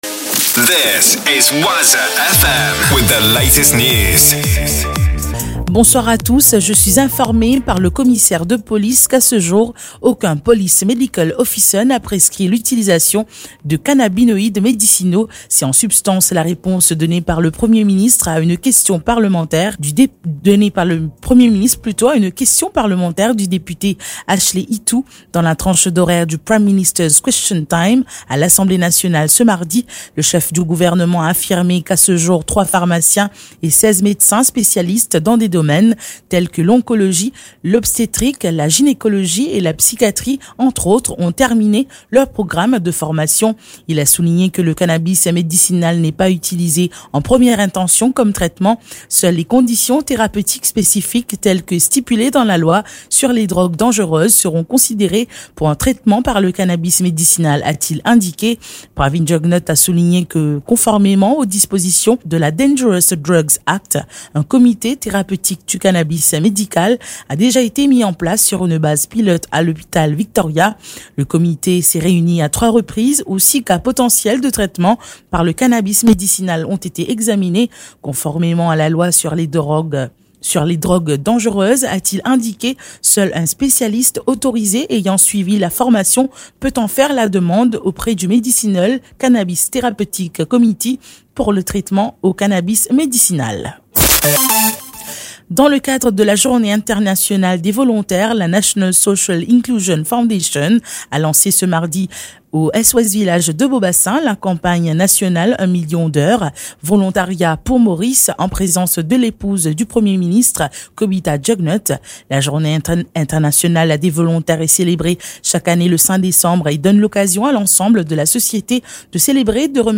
NEWS 19H - 5.12.23 -